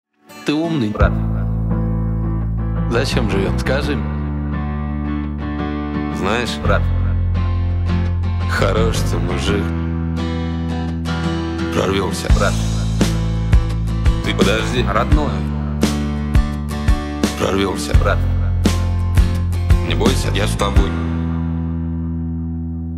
душевные
добрые